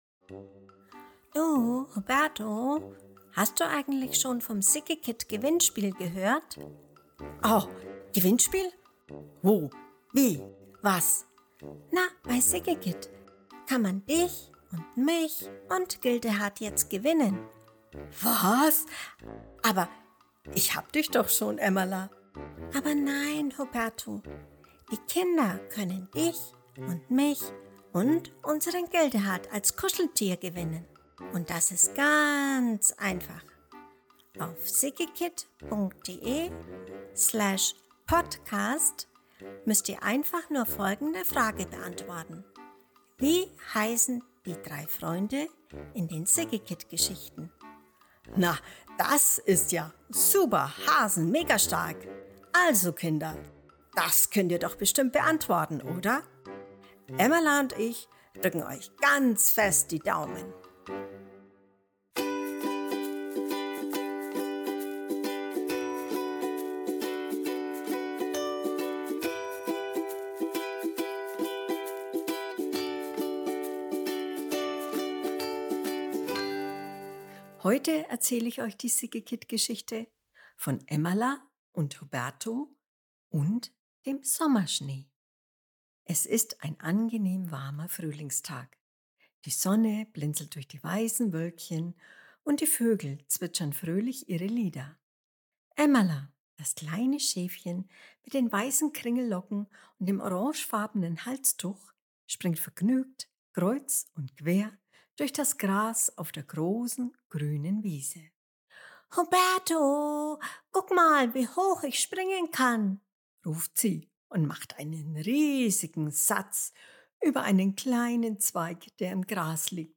Mai 2025 Kinderblog Jahreszeiten, Vorlesegeschichten, Emmala & Huberto „Huch?